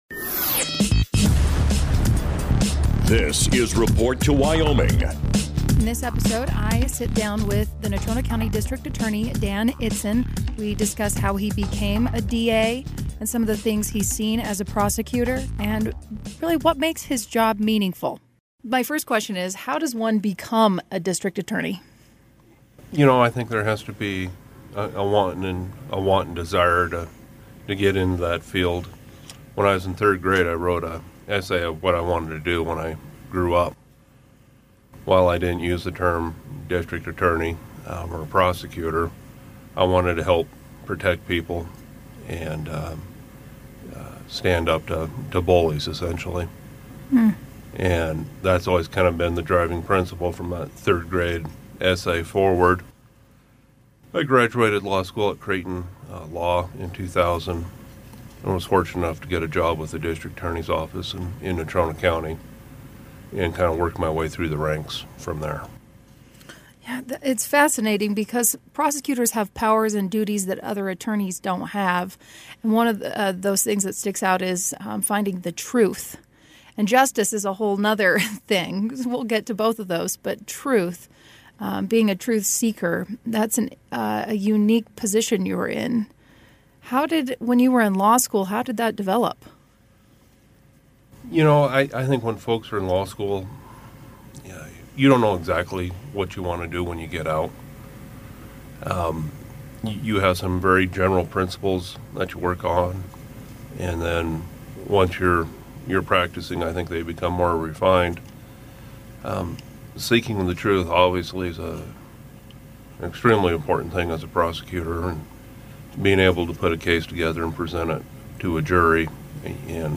Report to Wyoming talks with the Natrona County District Attorney, Dan Itzen, about how he became a prosecutor and what that experience has been like thus far.